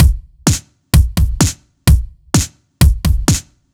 Index of /musicradar/french-house-chillout-samples/128bpm/Beats
FHC_BeatD_128-02_KickSnare.wav